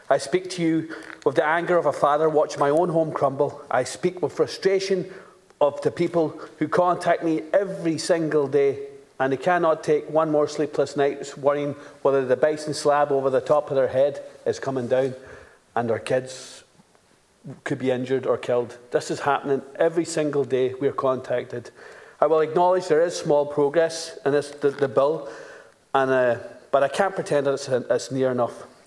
The second stage of the bill introducing amendments to Defective Concrete Block redress legislation were carried out in the Dáil chamber yesterday evening, with Donegal TDs having their voices heard.
Deputy Ward told last night’s debate the Oireachtas Library and Research Service confirmed they could not analyse the Bill because it was published too late.